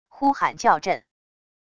呼喊叫阵wav音频